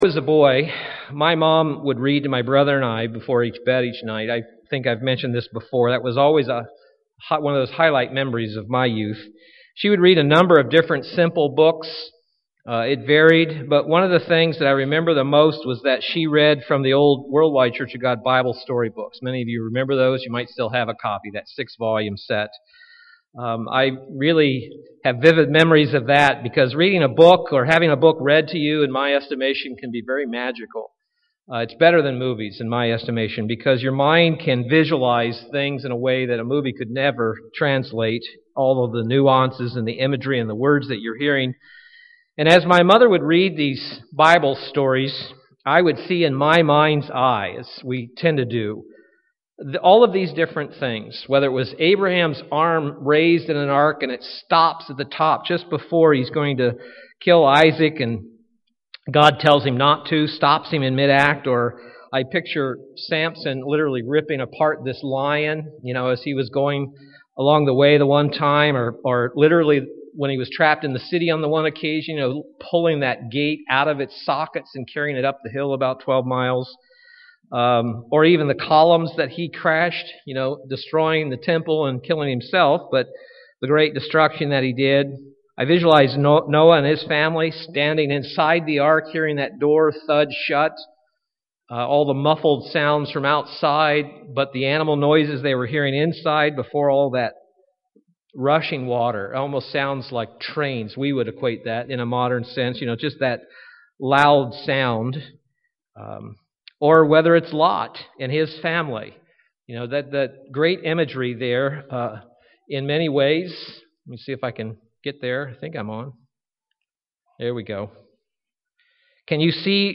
Given in Milwaukee, WI
Print Remember Lot's wife UCG Sermon Studying the bible?